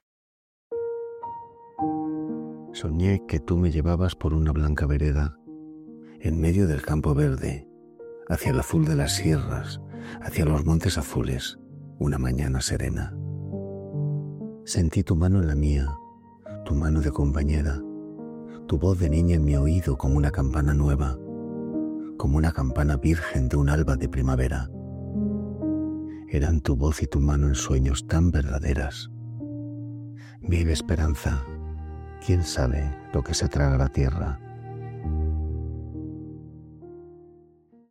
Machado.-Sone-que-tu-me-llevabas-enhanced-v2.-Musica.mp3